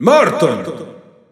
Announcer pronouncing Morton in Italian.
Morton_Italian_Announcer_SSBU.wav